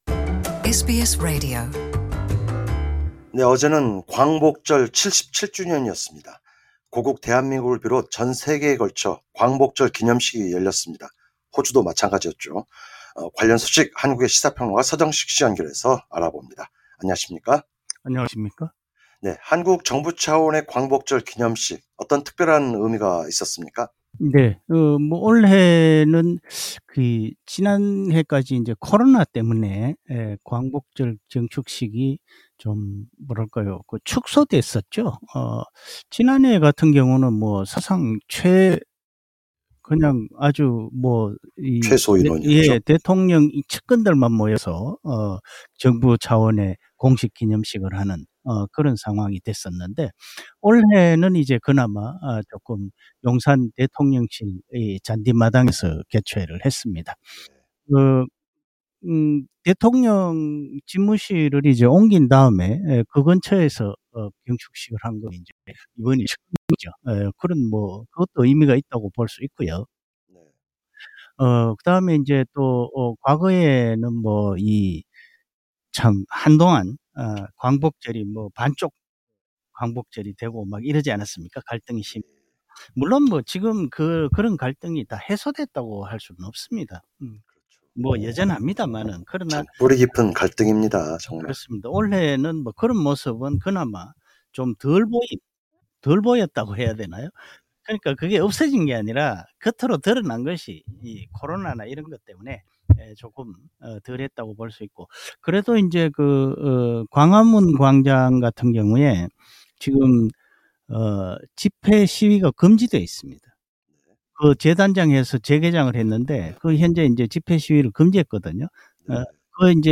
해설: 시사 평론가